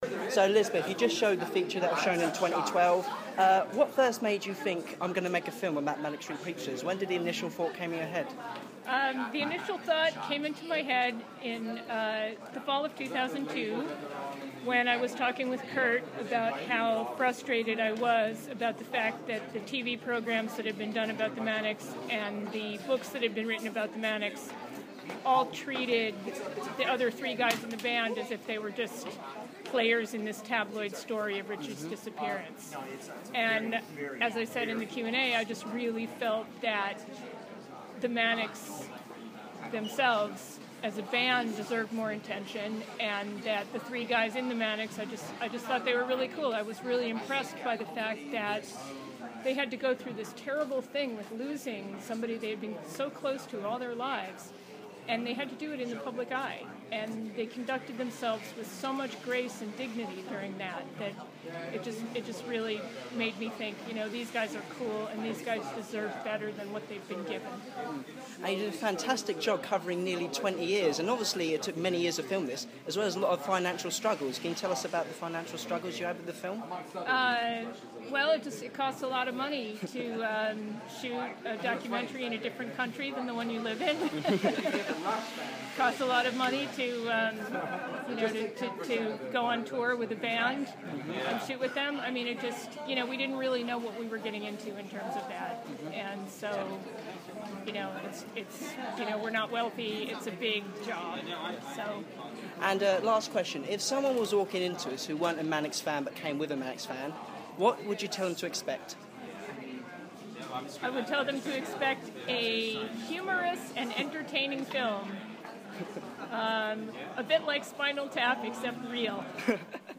Recorded at Soho Curzon Cinema